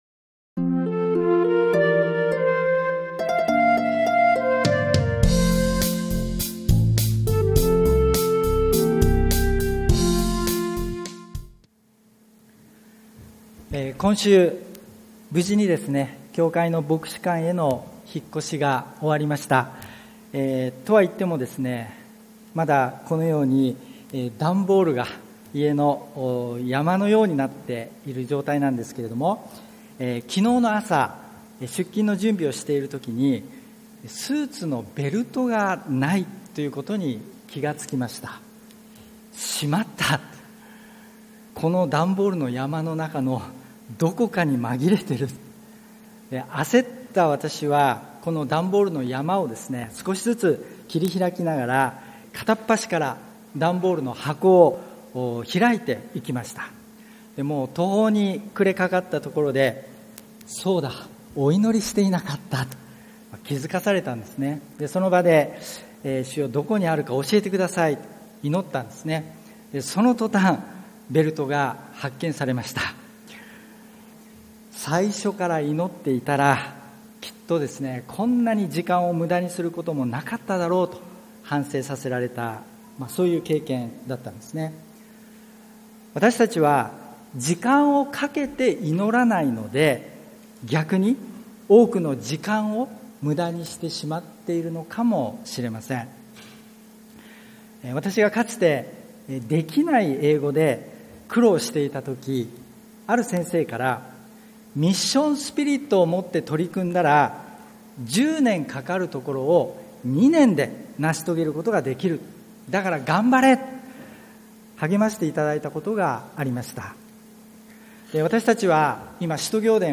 礼拝メッセージ176 信徒から弟子へ